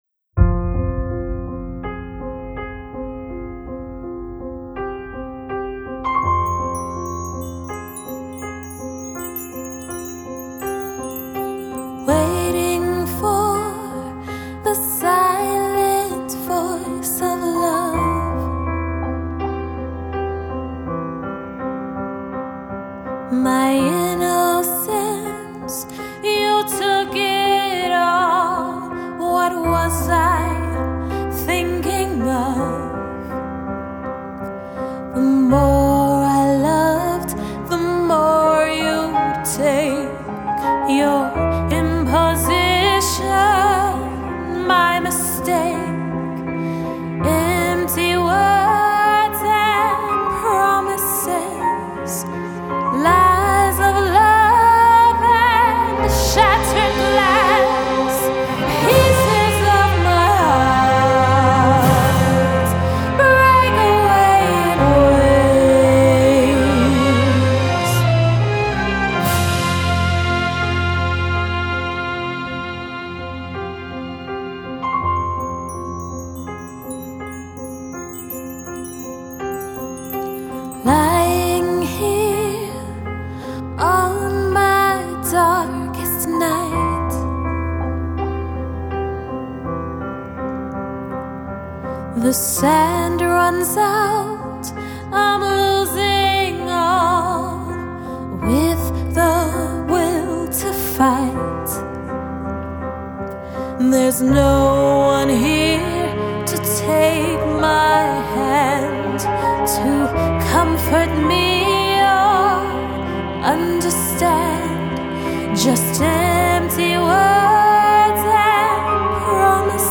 A Gothic Drama
Solo violin and viola